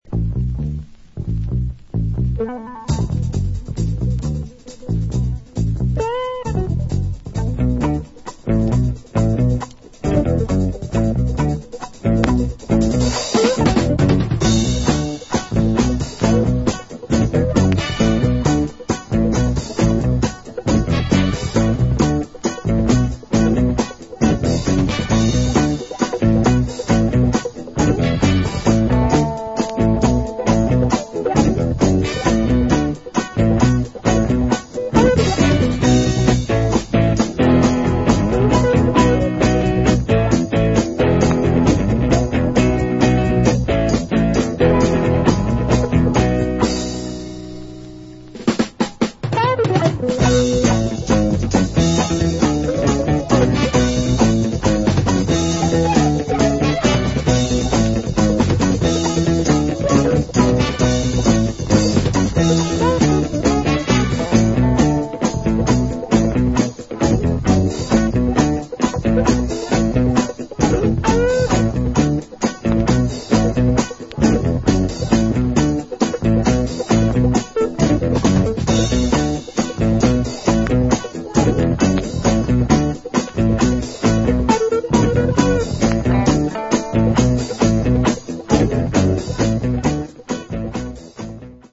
Great Funky Soul dancer from 1972.
This is the thumping instrumental version!
70's Soul